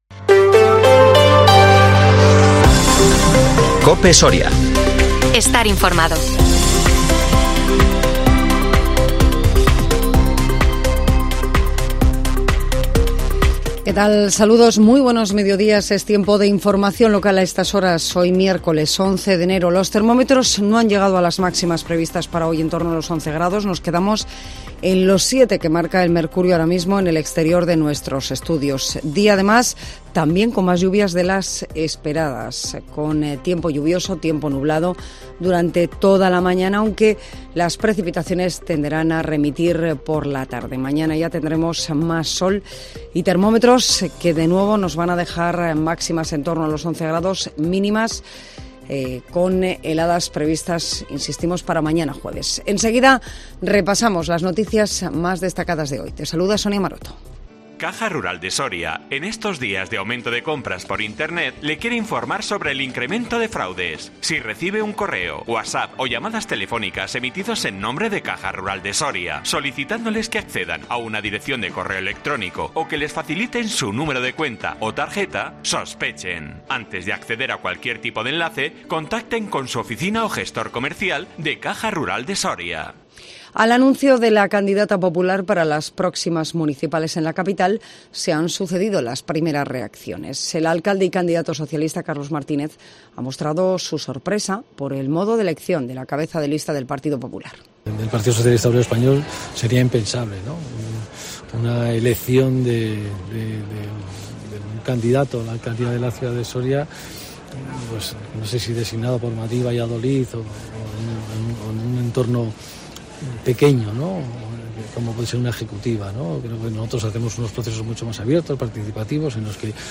INFORMATIVO MEDIODÍA COPE SORIA 11 ENERO 2023